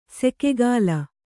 ♪ sekegāla